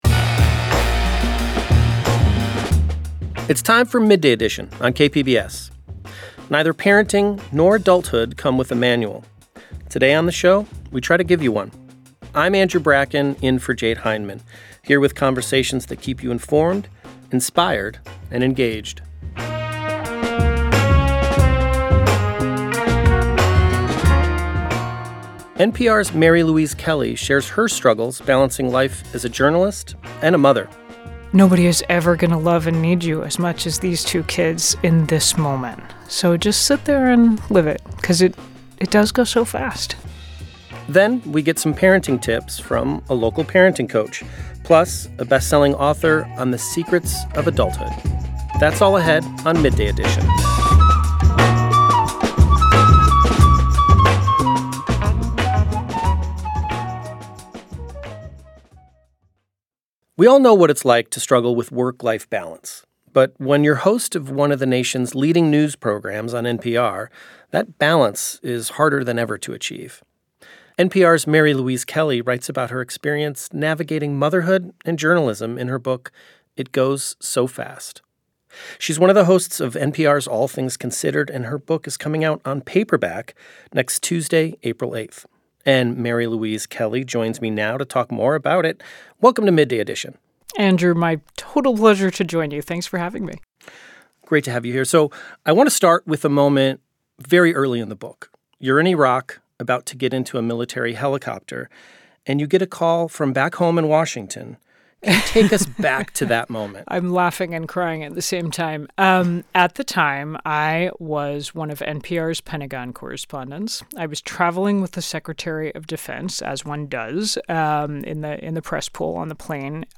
Guests share diverse perspectives from their expertise and lived experience.